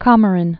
(kŏmər-ĭn), Cape